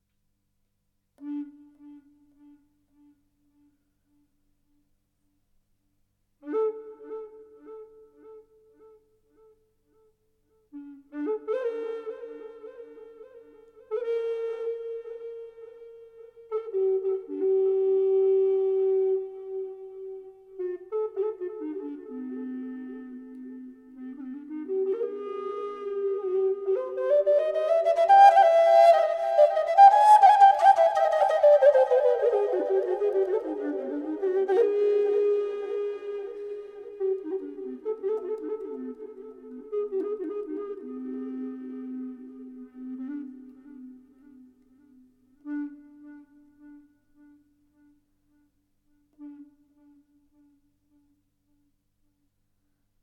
Aus dem Repertoire: Moderne